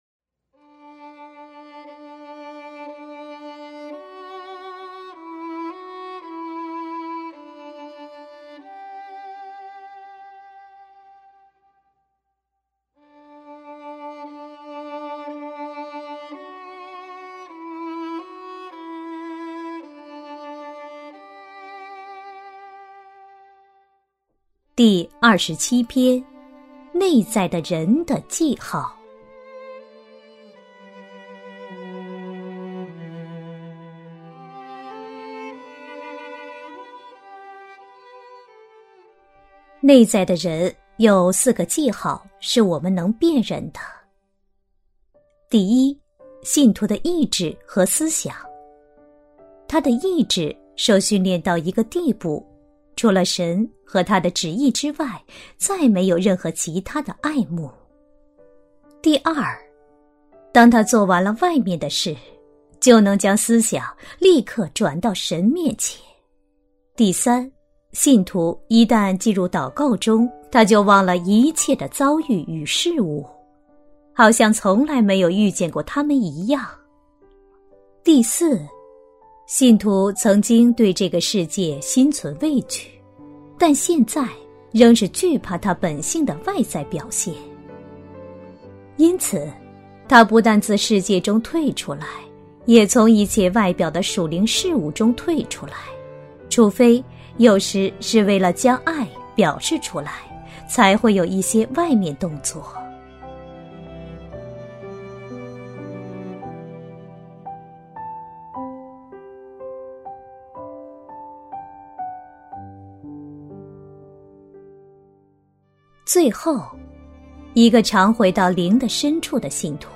首页 > 有声书 | 灵性生活 | 灵程指引 > 灵程指引 第二十七篇：“内在的人”的记号